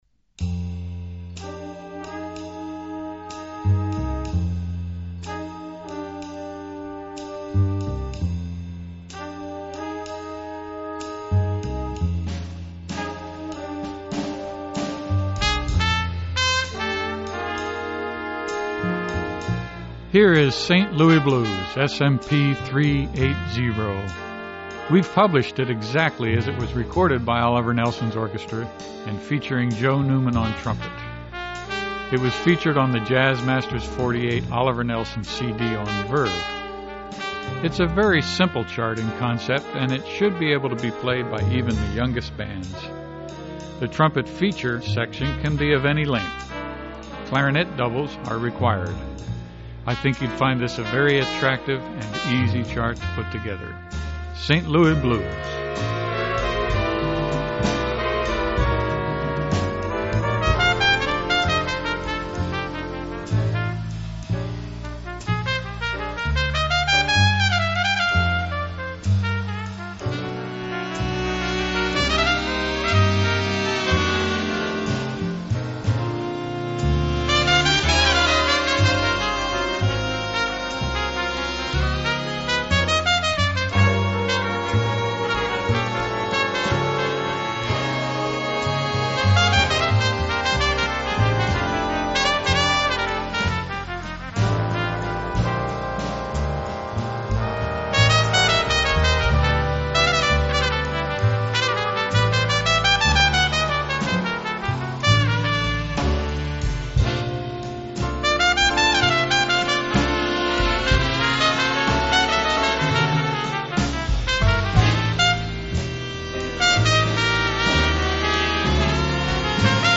on trumpet
Clarinet doubles are required.